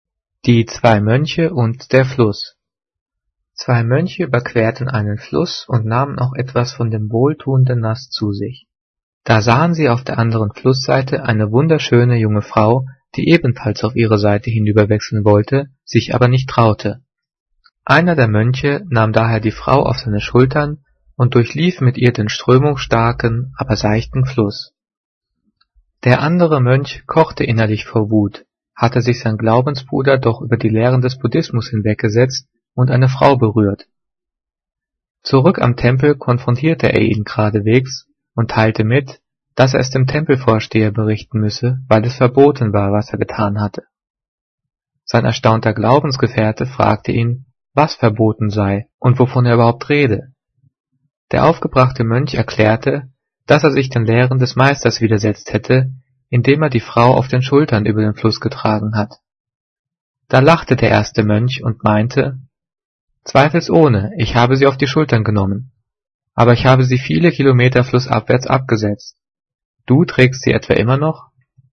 Gelesen:
gelesen-die-zwei-moenche-und-der-fluss.mp3